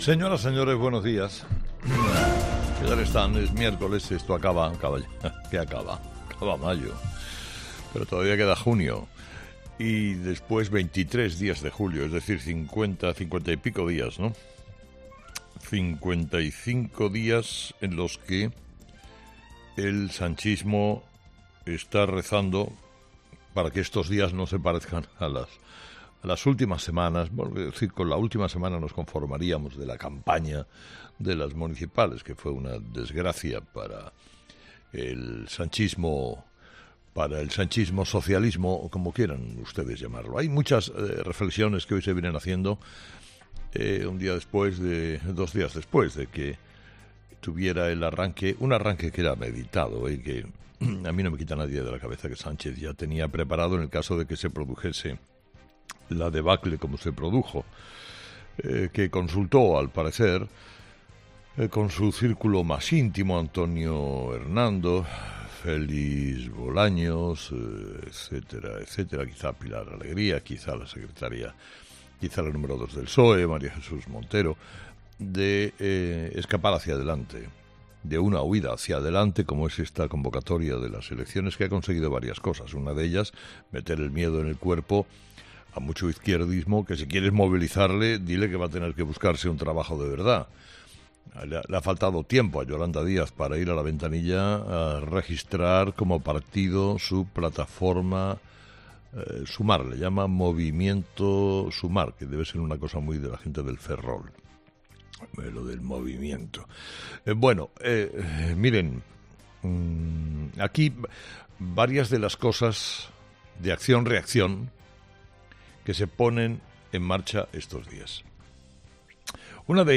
Escucha el análisis de Carlos Herrera a las 06:00 horas en Herrera en COPE este miércoles 31 de mayo de 2023
Carlos Herrera, director y presentador de 'Herrera en COPE', comienza el programa de este miércoles analizando las principales claves de la jornada, que pasan, entre otros asuntos, por los primeros movimientos para las elecciones generales de Pedro Sánchez.